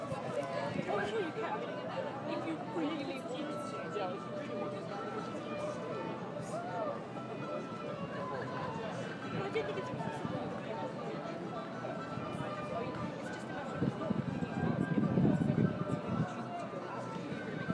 Warm Friday evening outside SOAS atmos :-)